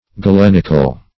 Galenic \Ga*len"ic\, Galenical \Ga*len"ic*al\, a.